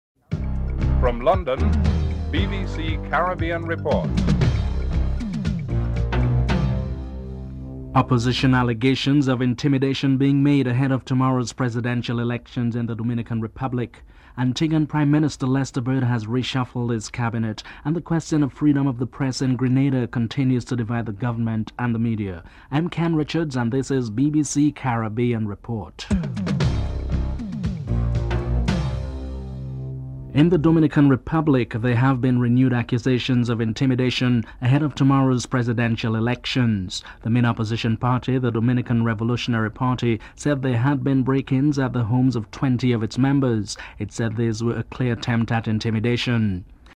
3. Antiguan Prime Minister Lester Bird has reshuffled his cabinet. Prime Minister Lester Bird is interviewed (05:58-08:24)